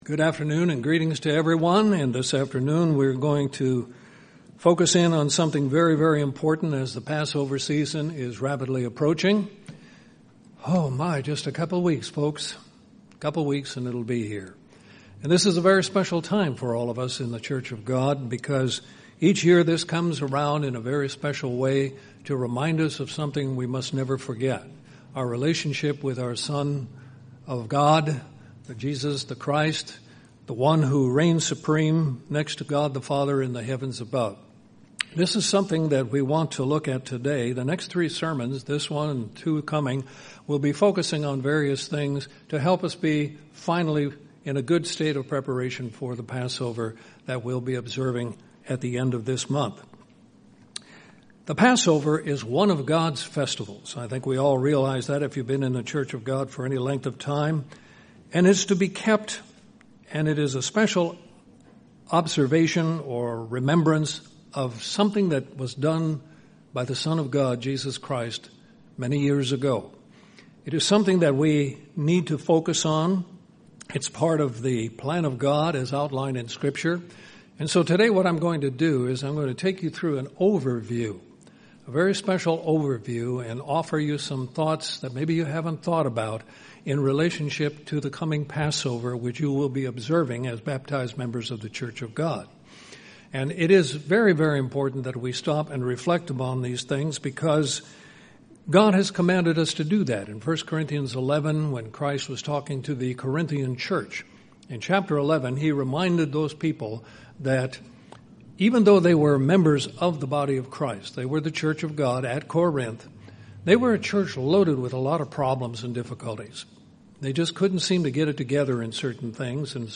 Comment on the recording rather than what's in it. Given in Columbus, GA Central Georgia